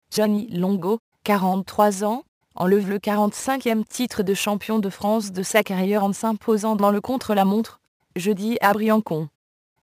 Texte de d�monstration lu par Juliette (AT&T Natural Voices; distribu� sur le site de Nextup Technology; femme; fran�ais)